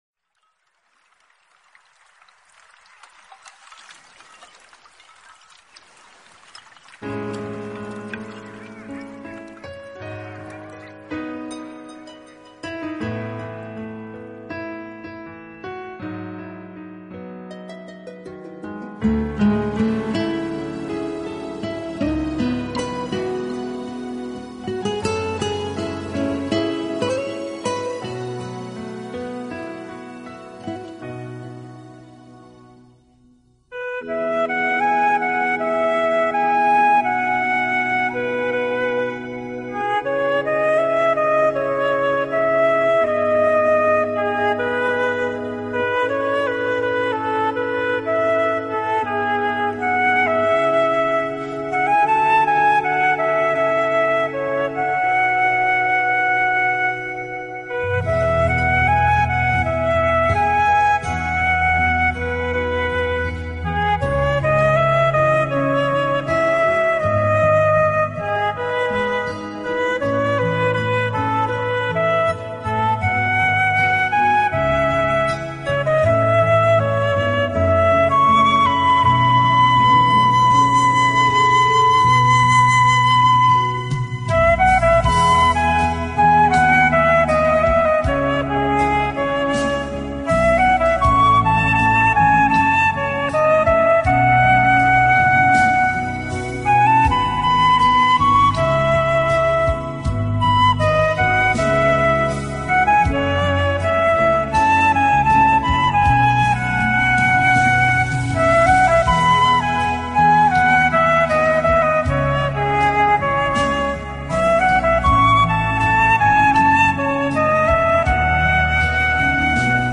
纯音长笛
本专辑用长笛吹奏，长笛乐色清新、透彻，色调是冷的。
音色柔美清澈，音域宽广，中、高音区明朗如清晨的一缕阳光，低音区婉约如冰澈的月色，
而且擅长花腔，演奏技巧华丽多样，在较高的音域中就像鸟鸣相仿。